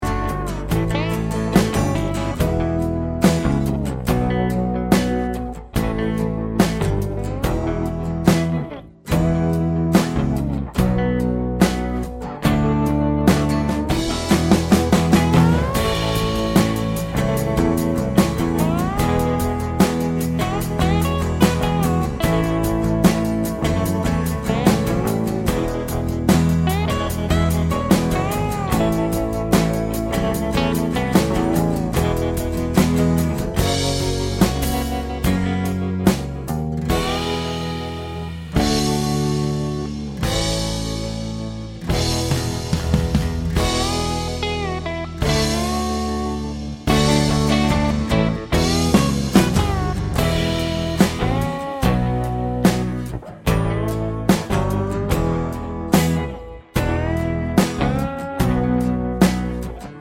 no Backing Vocals Rock 4:49 Buy £1.50